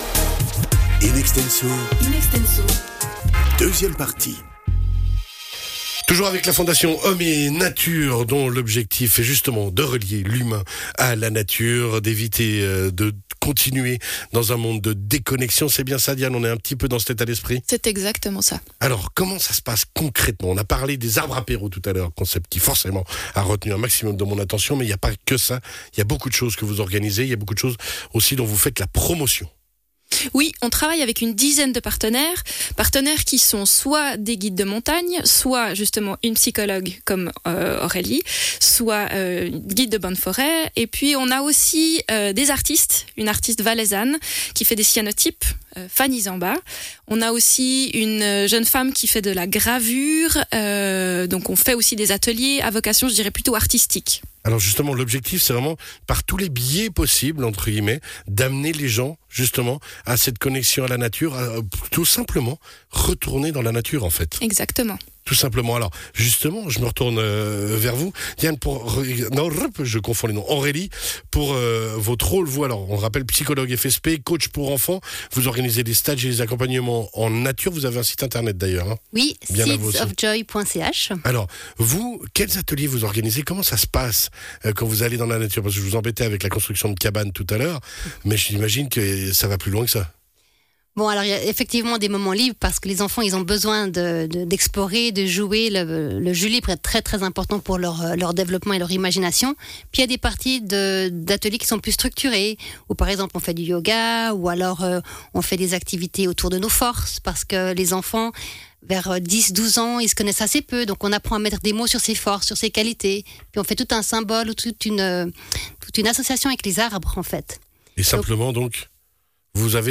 Interview de Radio Chablais sur la connexion à la nature
Découvrez notre interview à trois voix.